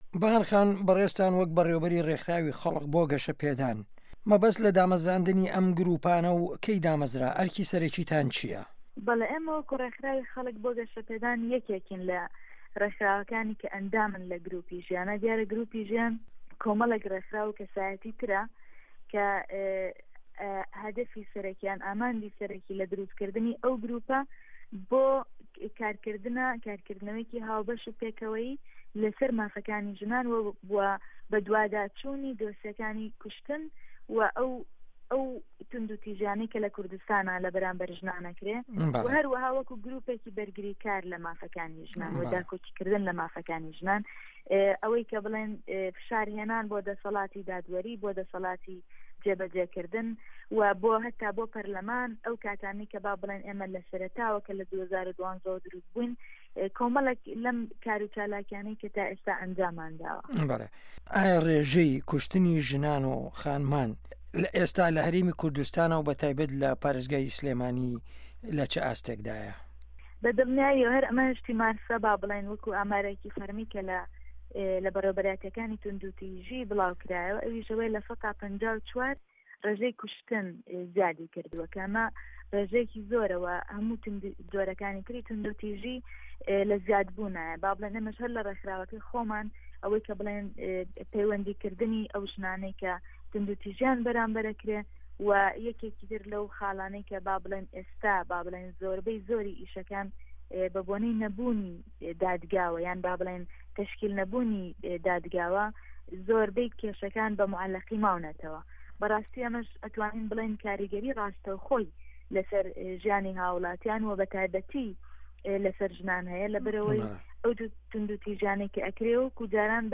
گفتوگۆ